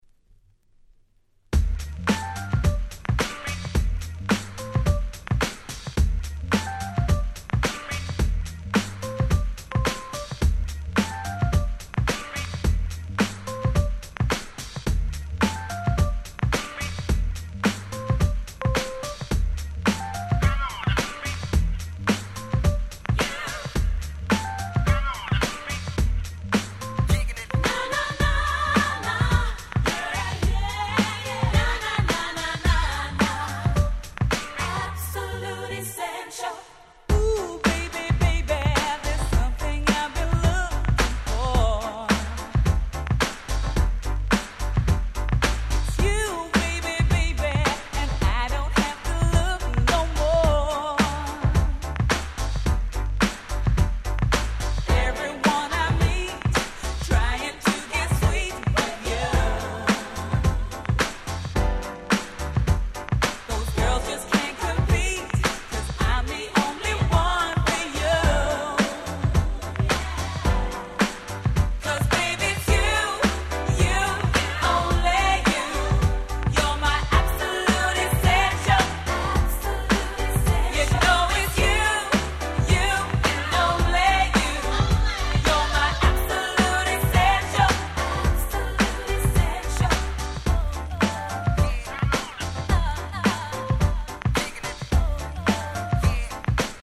Nice UK R&B !!